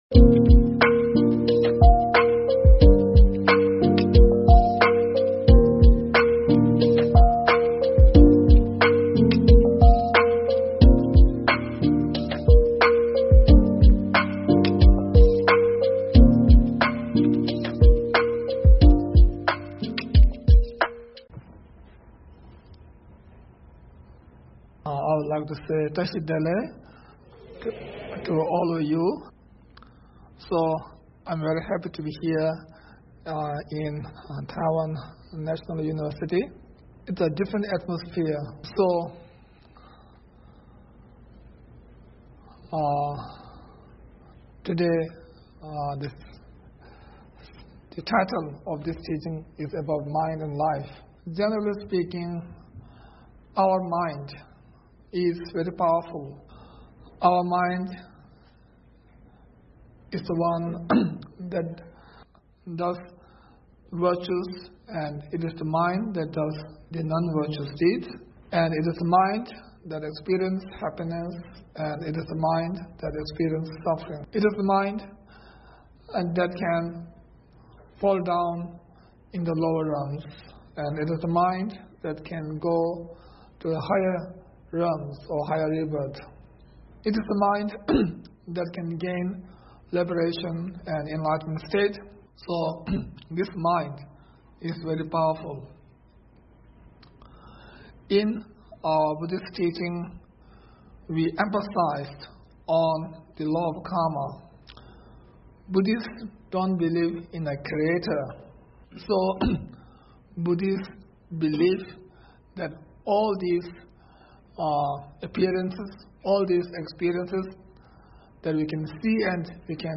3.Mind and Life_H.H. the 42nd Sakya Trizin's Dharma Teaching Given in 2014_The Sakya Tradition
Venue: Sakya Ling, Reading, U.K.